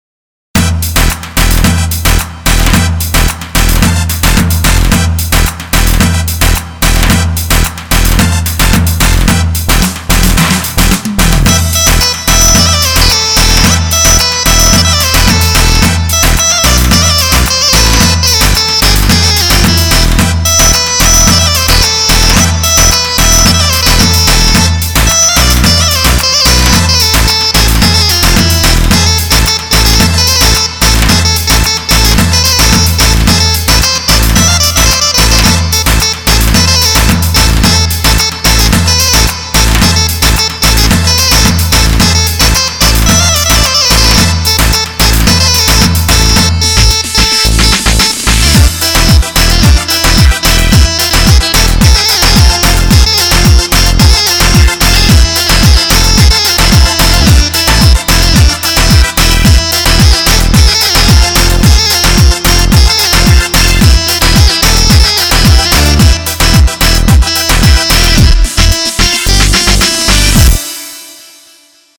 Yamaha Tyros 2 - Rakkas Mix Demo